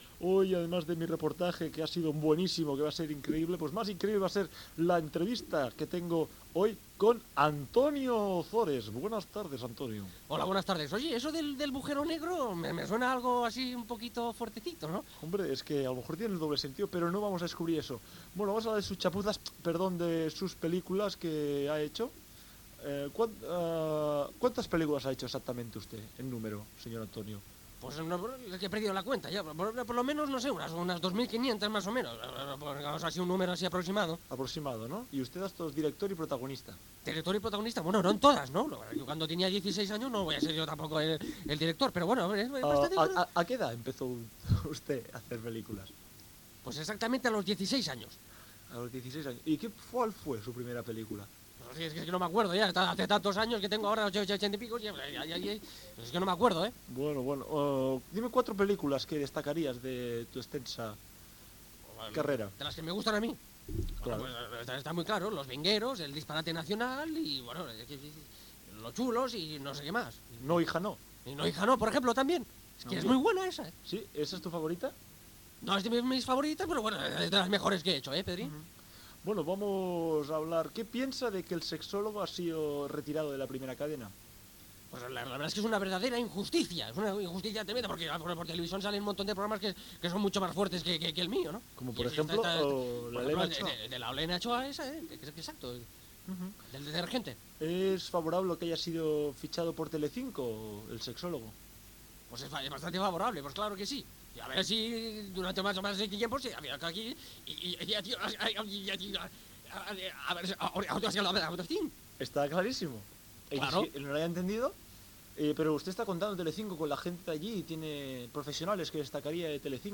Entrevista fictícia
Gènere radiofònic Entreteniment